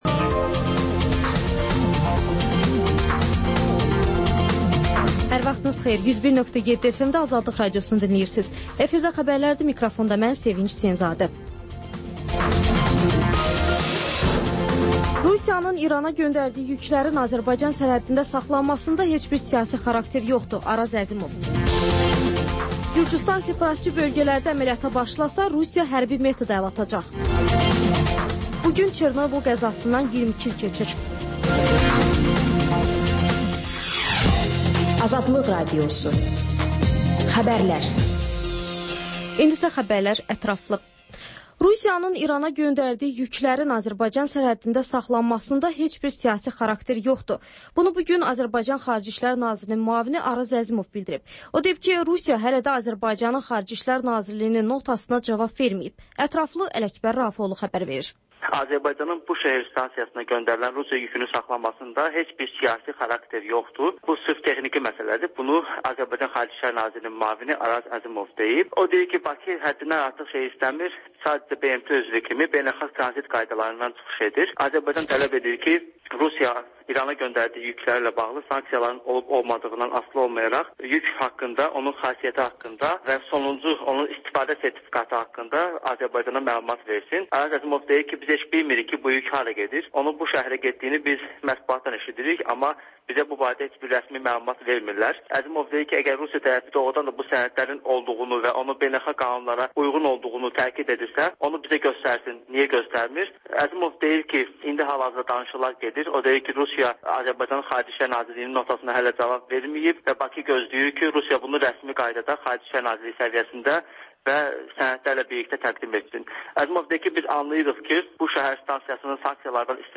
Xəbərlər, QAYNAR XƏTT: Dinləyici şikayətləri əsasında hazırlanmış veriliş, sonda MÜXBİR SAATI